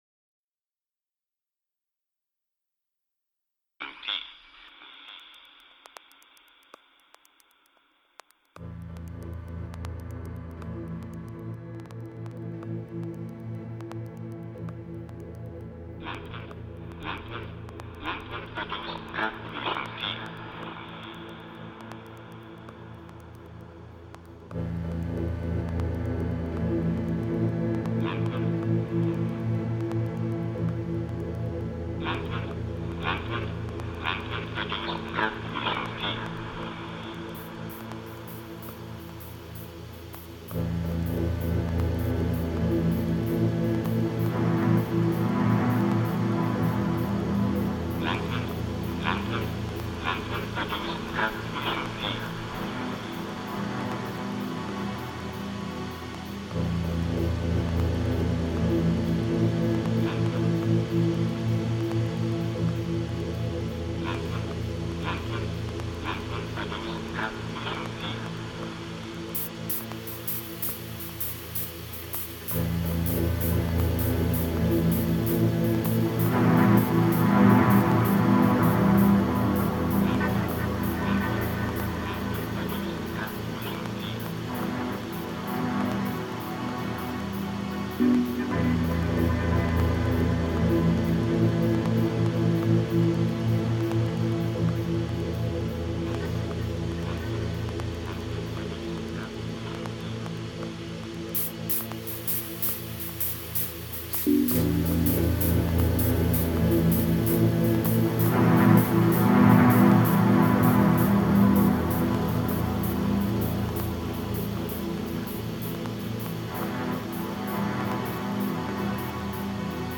Genre: Dub Techno.